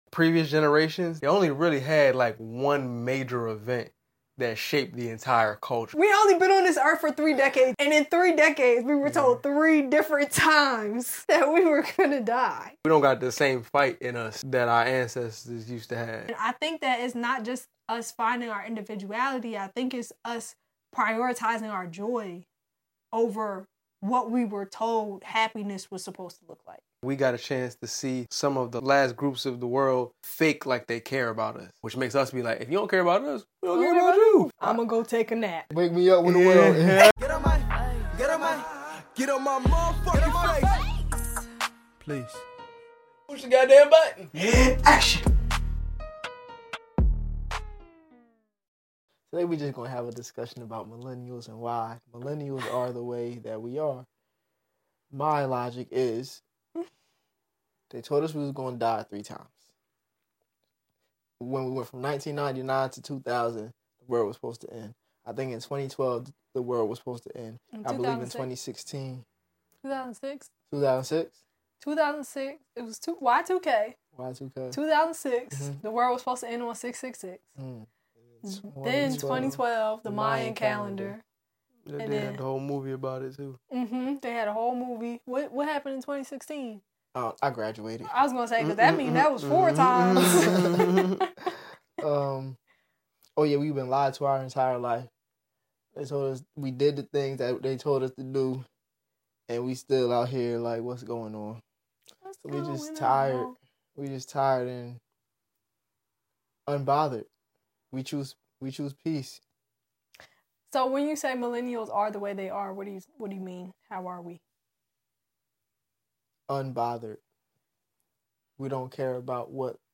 We love to laugh, create, and inspire while having unique and thought provoking conversations.